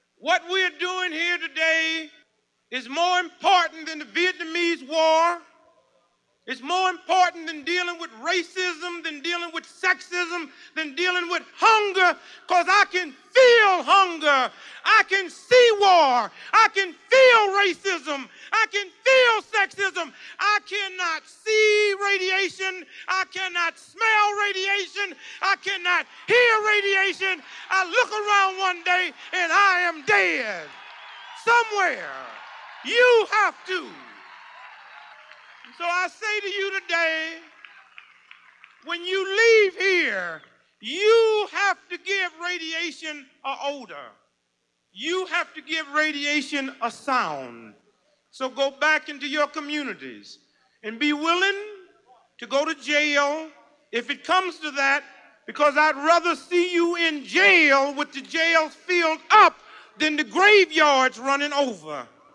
Nuclear weapons and nuclear power are simply two sides of the same coin. At a 28 March 1979 rally in Washington D.C. to Stop Nuclear Power, Dick Gregory summed up the imperative [